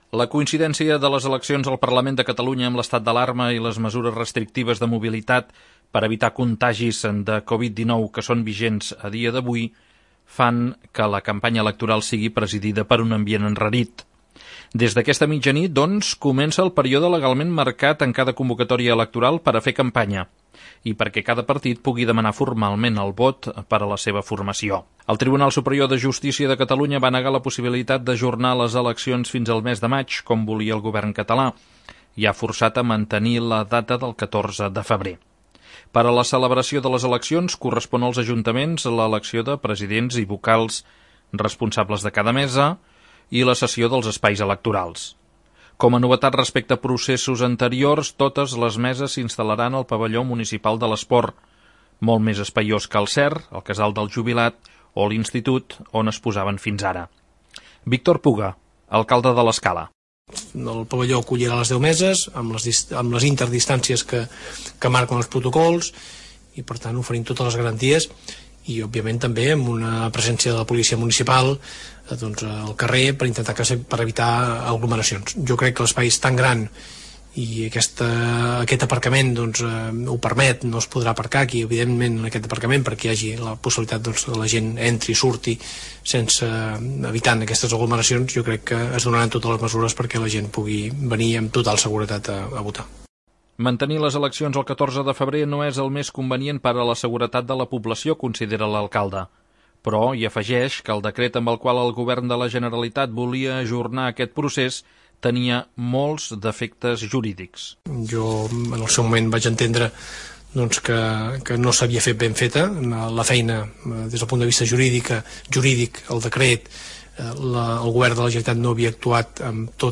Hem recollit les impressions de dos d'ells.